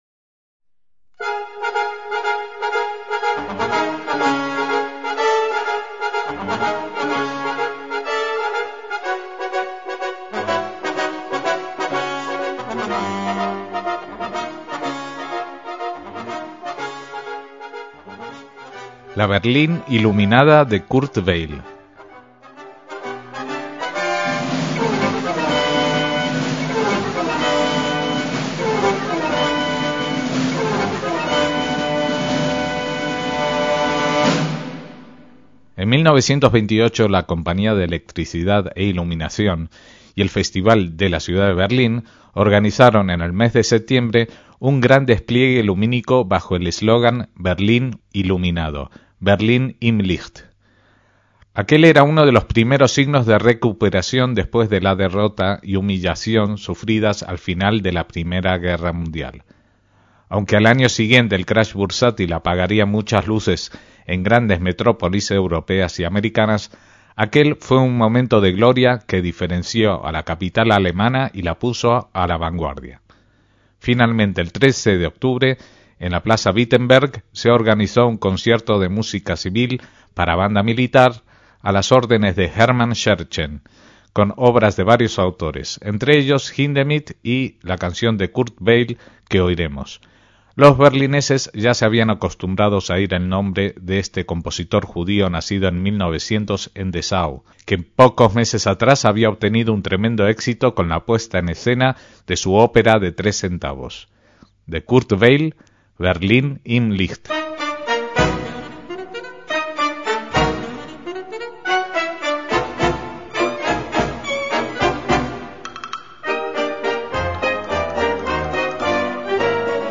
MÚSICA CLÁSICA - El compositor alemán Kurt Weill  (nacido en Dessau en 1900, hijo de un cantor de sinagoga, y fallecido en Nueva York en 1950) estudió composición musical en el Conservatorio de Berlín con Ferruccio Busoni.